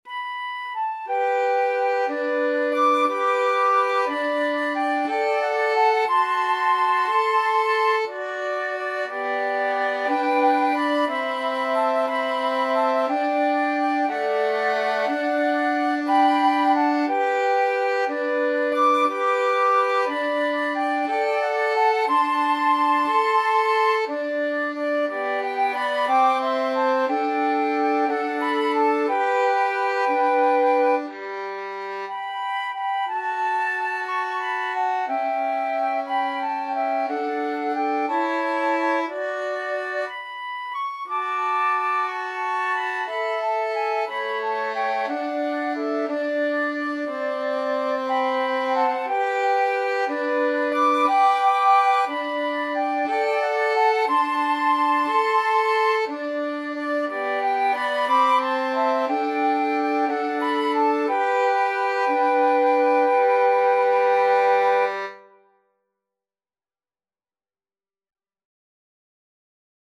Free Sheet music for Flexible Mixed Ensemble - 3 Players
Flute
Violin
G major (Sounding Pitch) (View more G major Music for Flexible Mixed Ensemble - 3 Players )
6/8 (View more 6/8 Music)
Maestoso . = c. 60
Classical (View more Classical Flexible Mixed Ensemble - 3 Players Music)